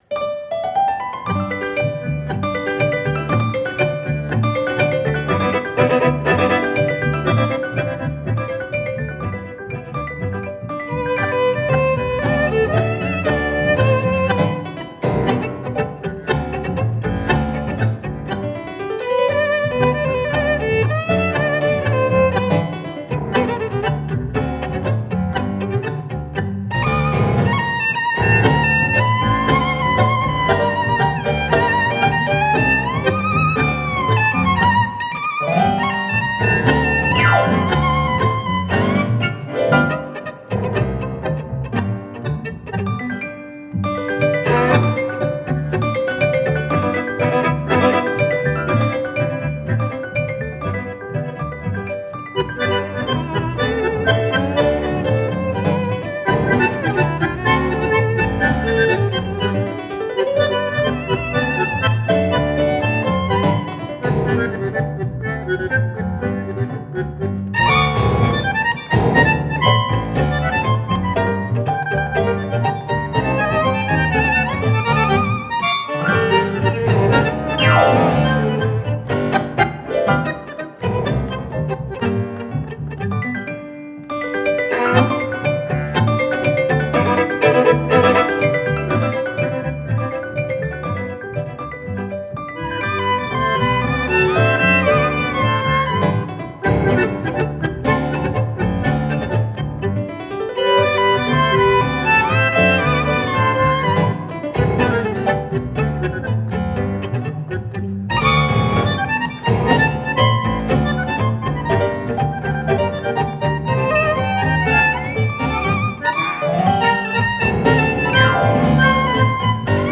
Танго
live